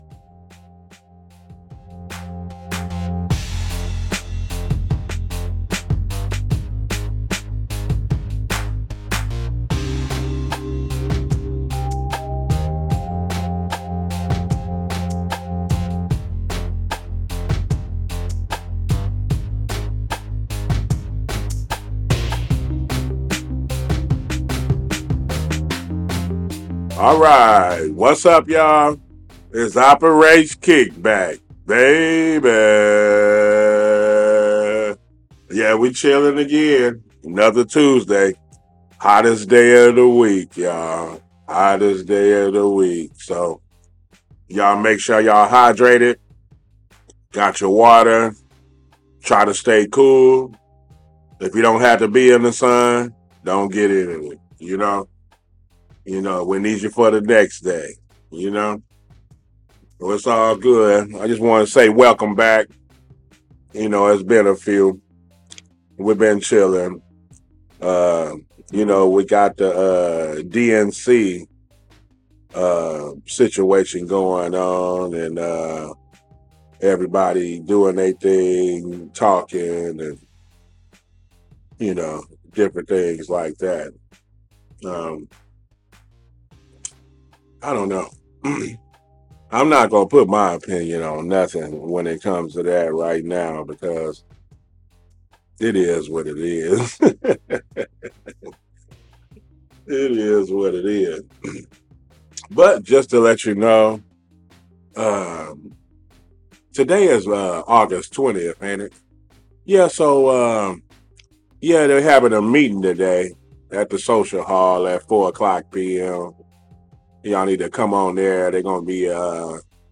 This episode of Operation KickBack aired live on CityHeART radio at 1pm Aug. 20.